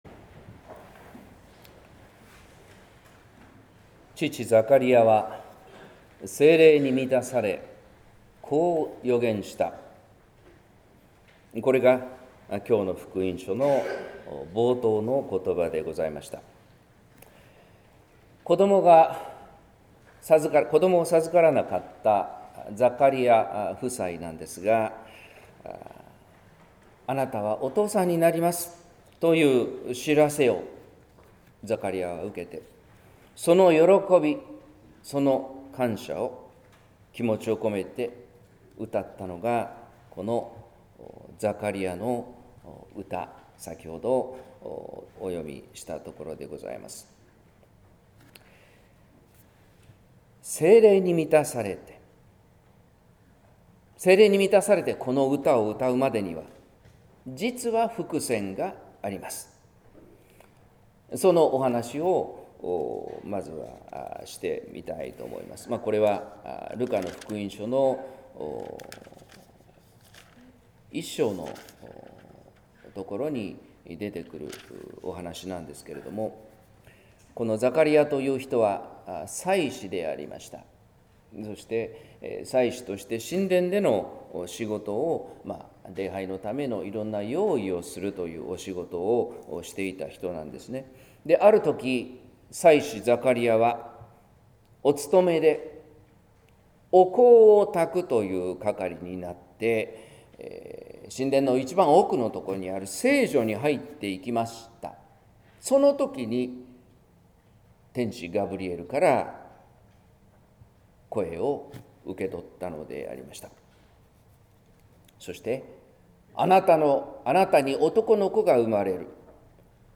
説教「平和を告げるあけぼのの光」（音声版） | 日本福音ルーテル市ヶ谷教会